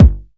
BD DM2-20.wav